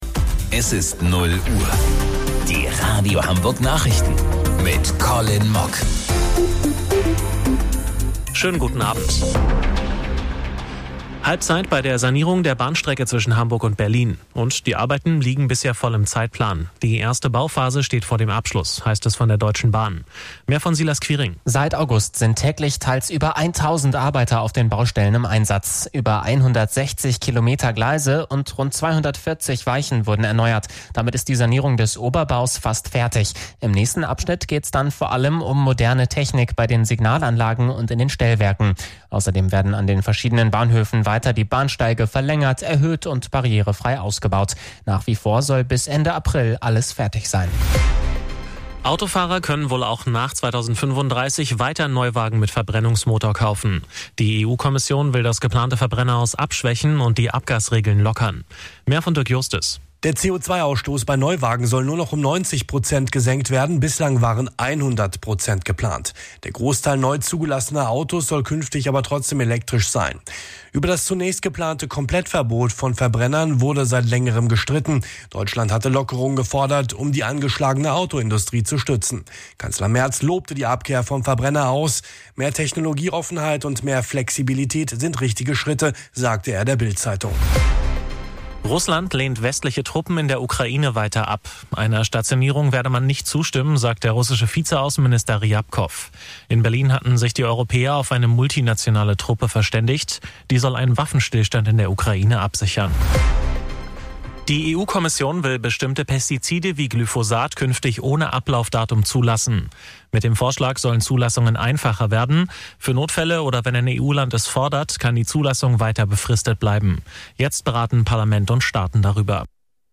Radio Hamburg Nachrichten vom 17.12.2025 um 00 Uhr